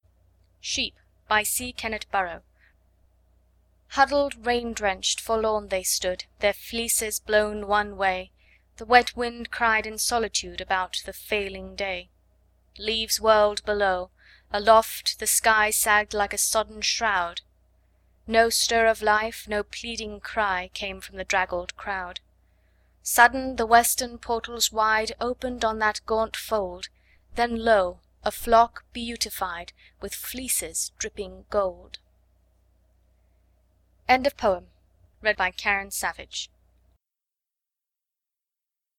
Sheep Poem Huddled, rain-drenched, forlorn they stood, Their fleeces blown one way; The wet wind cried in solitude About the failing day.